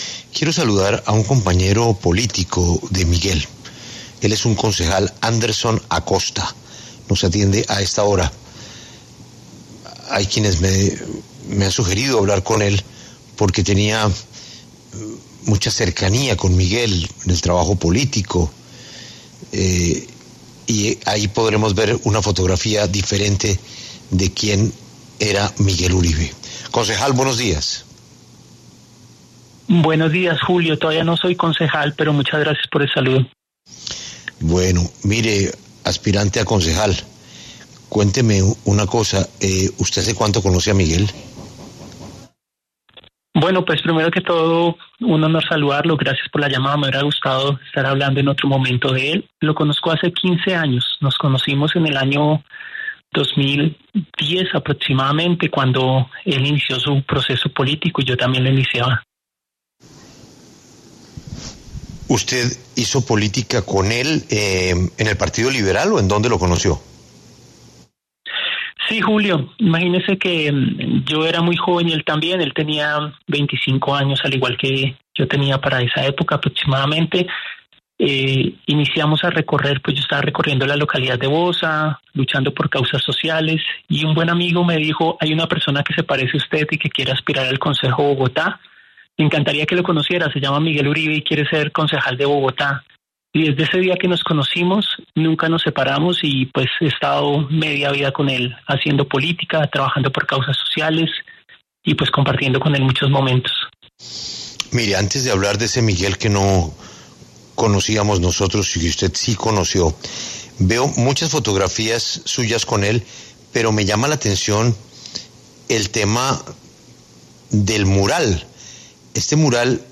Este lunes, 11 de agosto, habló en los micrófonos de La W, con Julio Sánchez Cristo, Anderson Acosta, quien fue alcalde de las localidades de Bogotá Fontibón (2022) y San Cristóbal (2020 y 2021) y además, amigo cercano de Miguel Uribe, para hablar sobre la muerte del senador y precandidato presidencial.